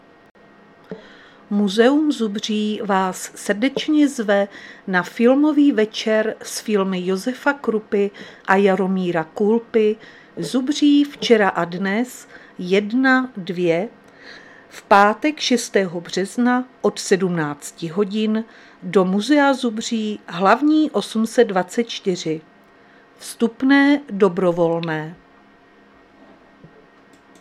Záznam hlášení místního rozhlasu 2.3.2026
Zařazení: Rozhlas